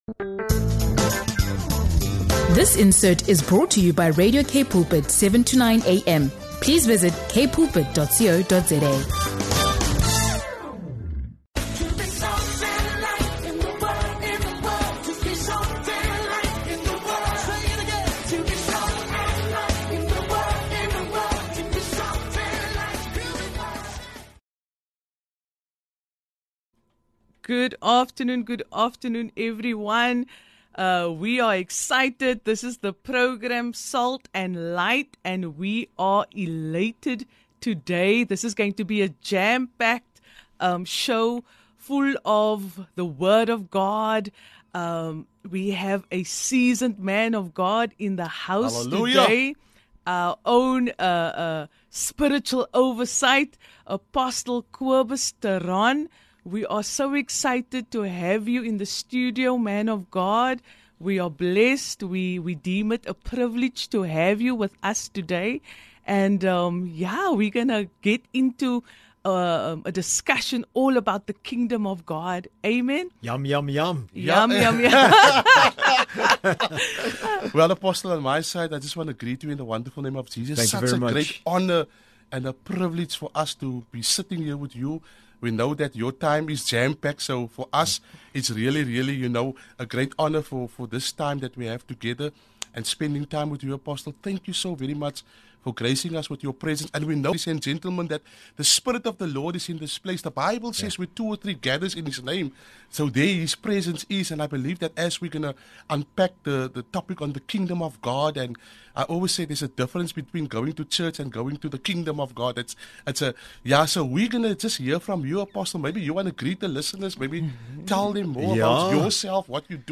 In this special conversation, we dive deep into the Kingdom of God and what it means to live the Kingdom Way of Life.